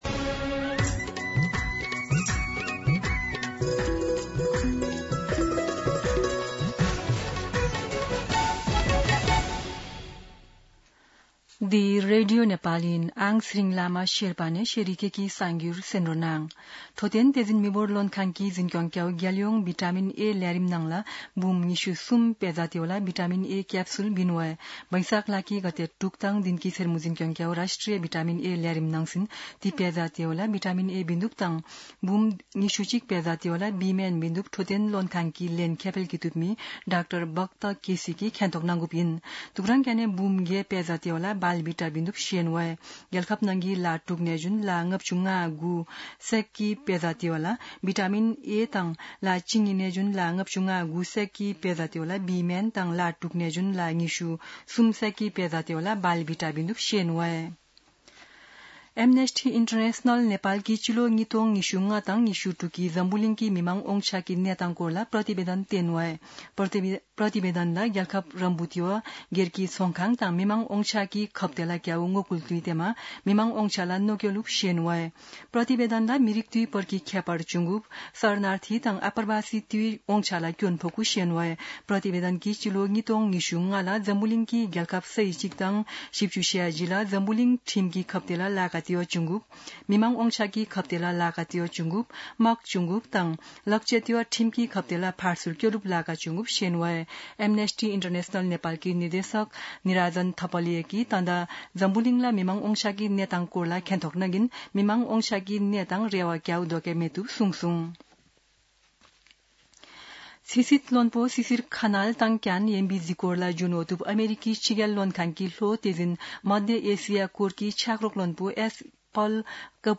शेर्पा भाषाको समाचार : ८ वैशाख , २०८३
Sherpa-News-08.mp3